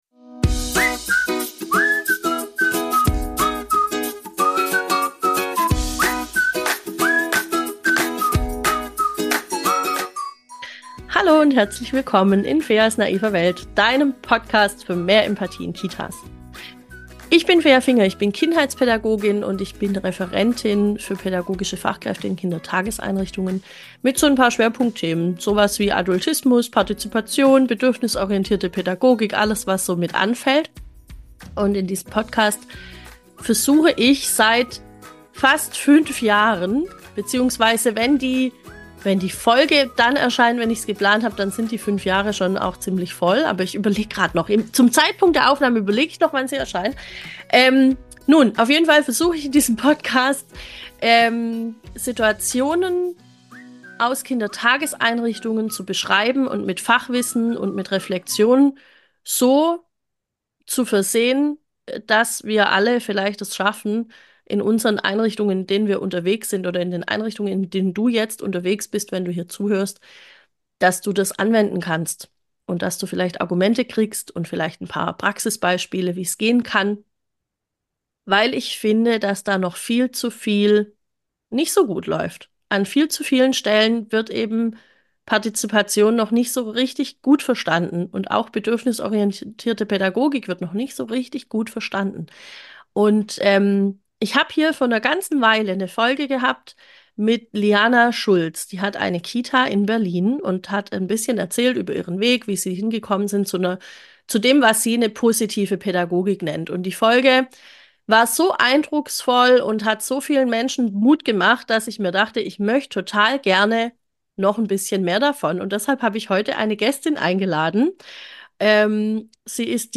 #168- Kinderrechte im Kita-Alltag leben- Interviewspecial